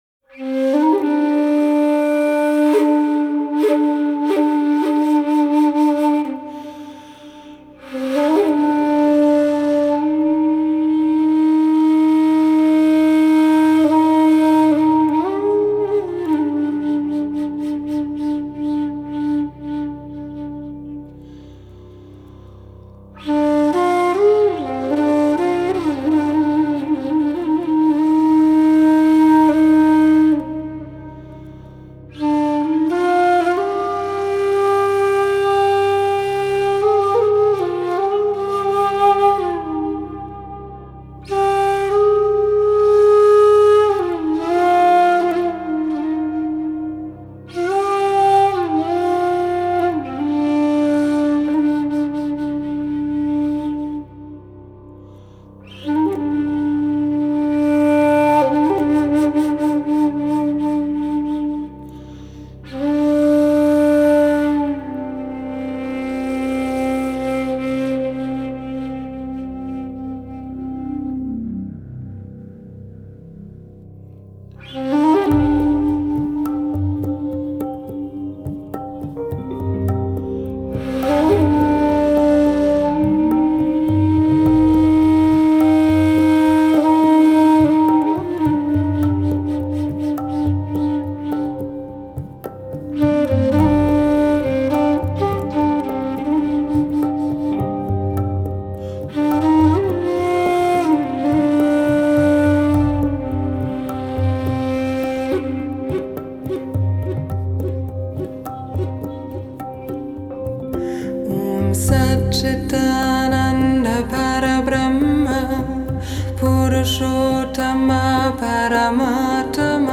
Медитативная музыка